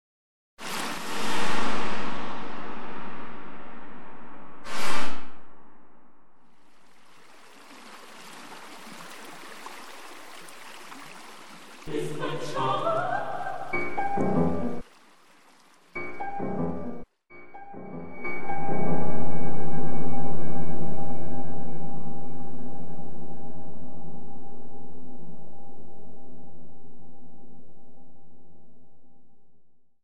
für Violoncello solo und elektroakustische Zuspielung